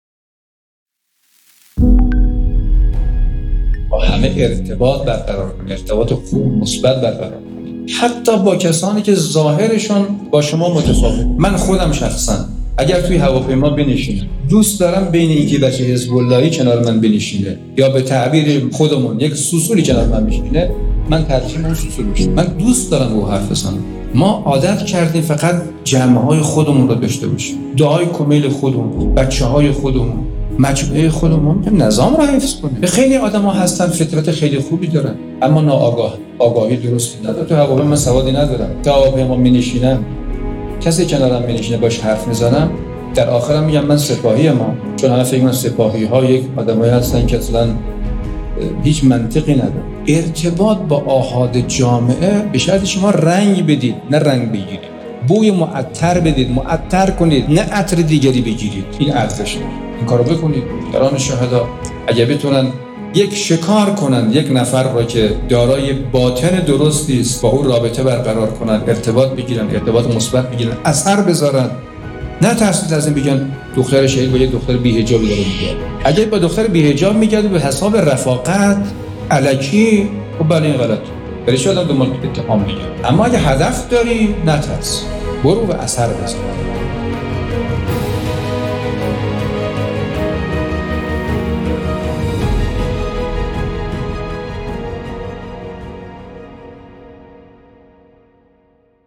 در این پادکست گزیده‌ای از بیانات ماندگار شهید حاج قاسم سلیمانی را با عنوان«ارتباط با مردم» می‌شنوید.